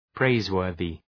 Προφορά
{‘preız,wɜ:rðı}